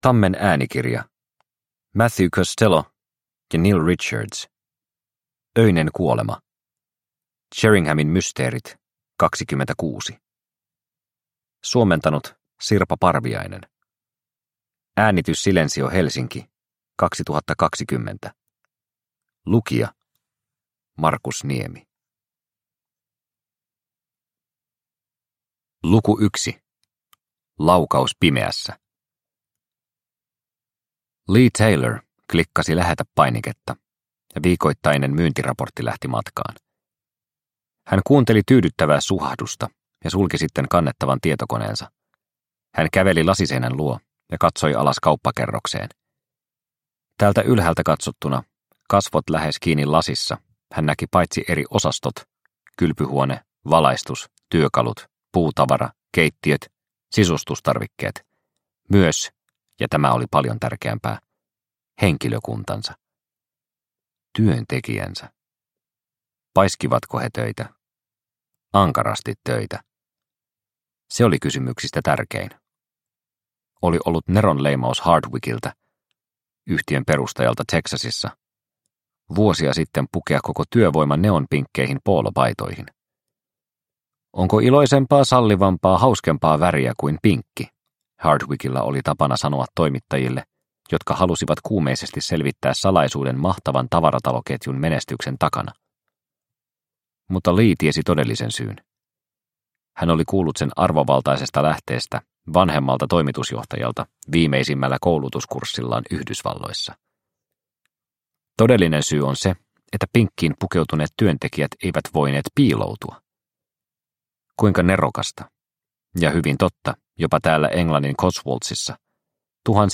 Öinen kuolema – Ljudbok – Laddas ner